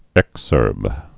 (ĕksûrb)